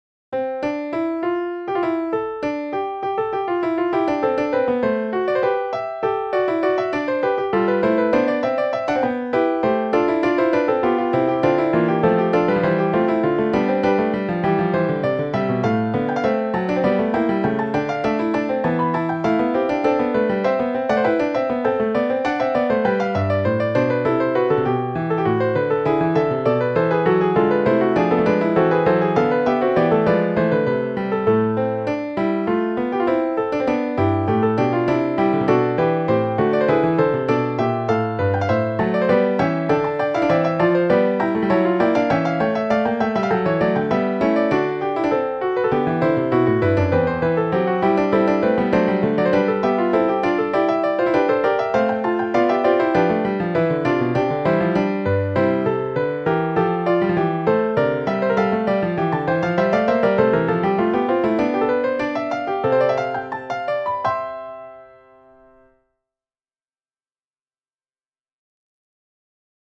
Fugue in C Major.mp3